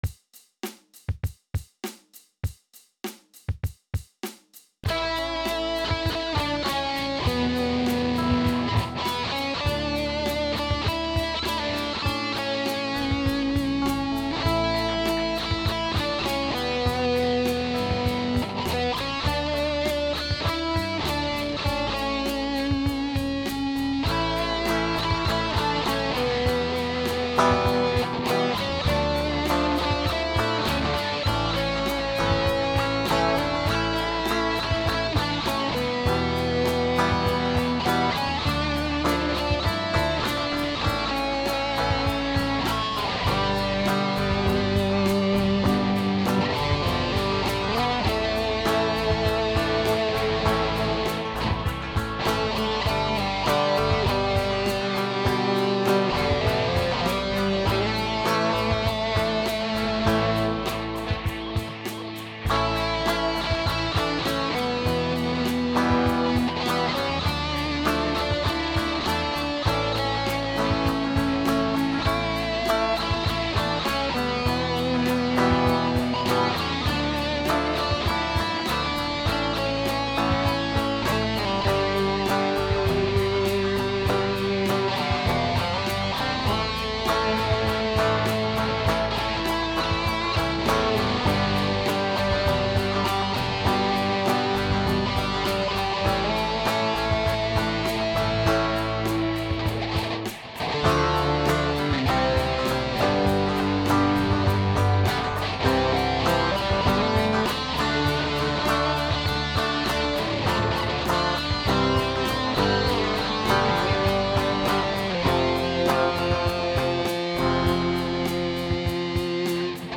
Dream On (multitrack demo).
This is an instrumental version of a song I wrote c.2004. I did record some vocals, but they're so bad that I've left them off for now (!).